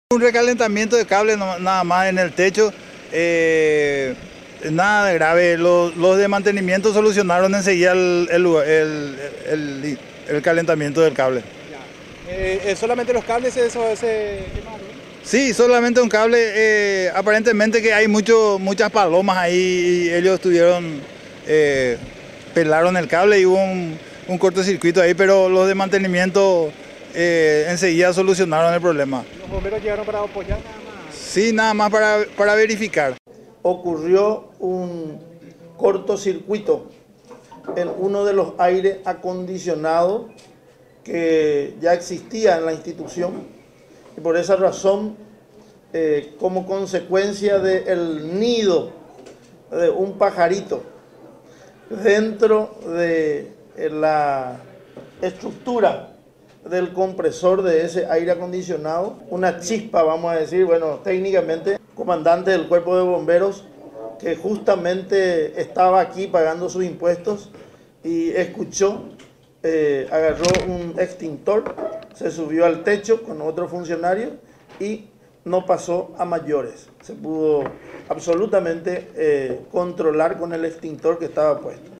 Al filo del mediodía, el intendente encarnaceno, Luis Yd, llamó a conferencia de prensa, para explicar lo acontecido, aclarando al personal de mantenimiento que sofocó el cortocircuito, se sumó un bombero que casualmente estaba en el edificio municipal, pagando sus impuestos.